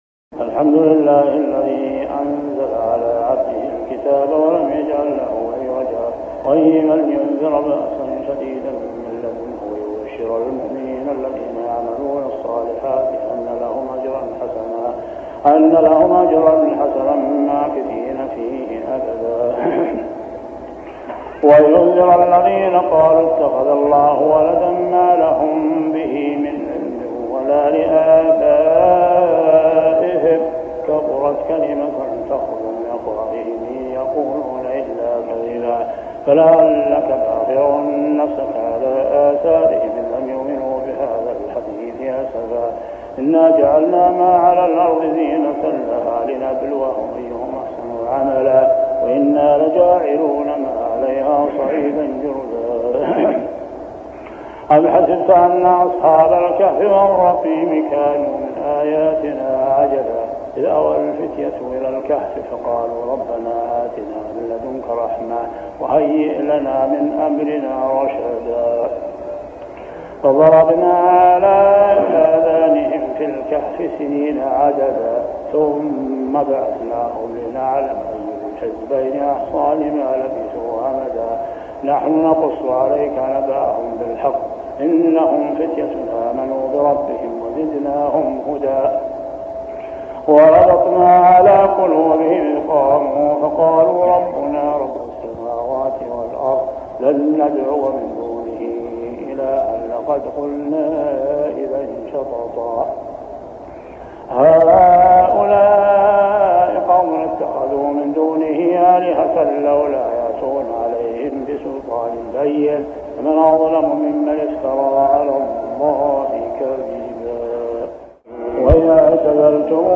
صلاة التراويح عام 1403هـ سورة الكهف 1-59 | Tarawih prayer Surah Al-Kahf > تراويح الحرم المكي عام 1403 🕋 > التراويح - تلاوات الحرمين